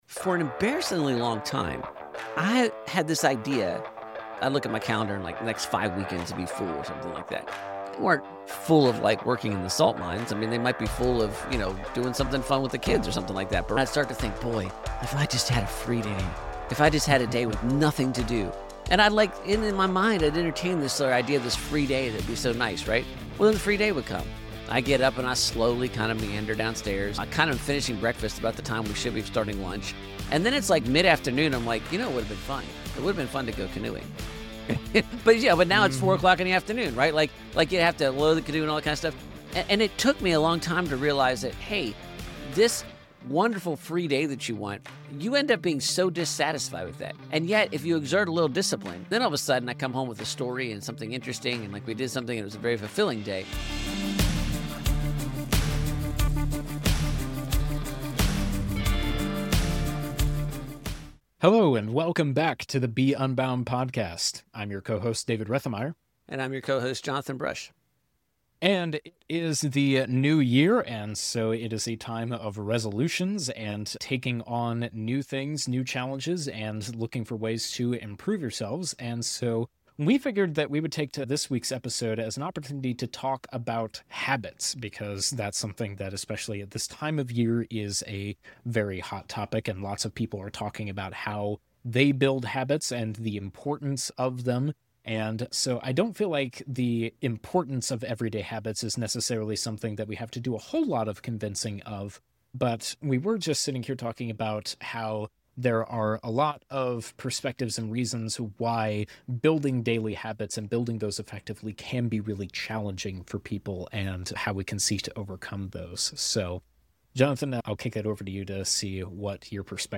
The Be Unbound Podcast teaches young adults and their parents how to ask the right questions, take action, and understand key truths that will enable them to live well, by showcasing conversations with guests who display what it looks like to be extraordinary at the ordinary.